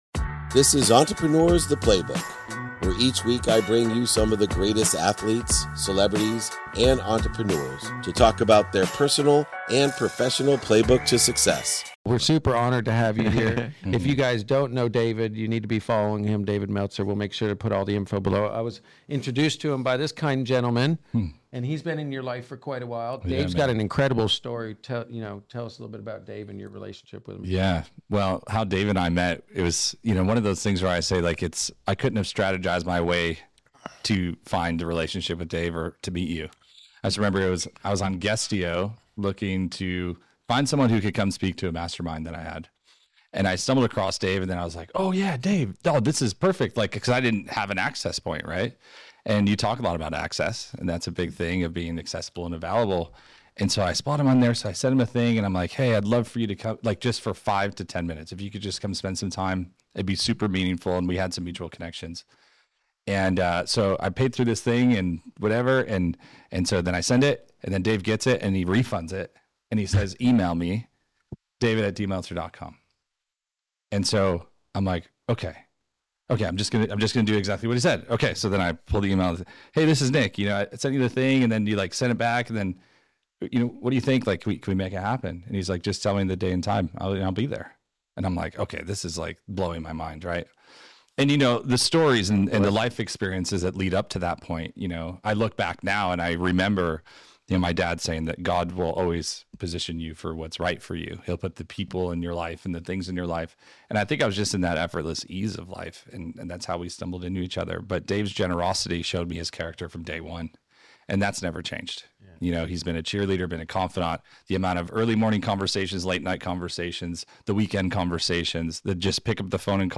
We discuss how helping others can lead to incredible opportunities and the significance of living with gratitude and faith. Join us for an inspiring conversation that highlights the importance of community, resilience, and the pursuit of one's true potential.